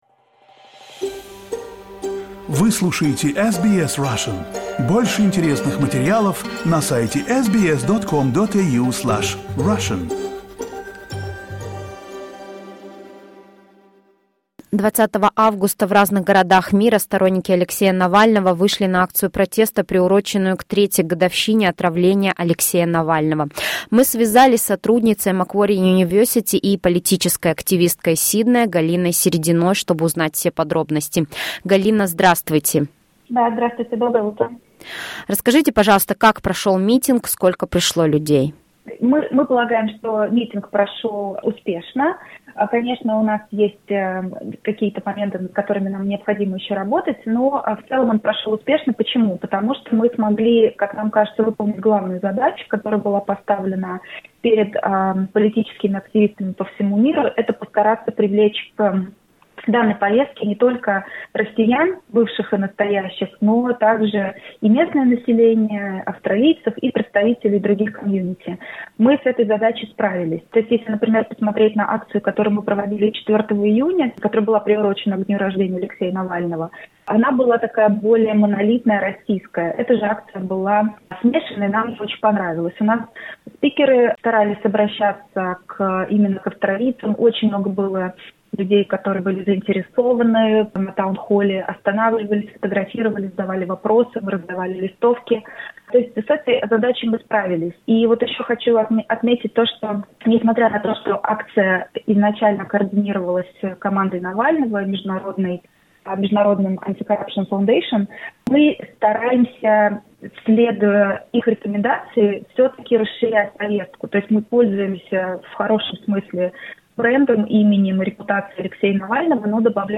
SBS Russian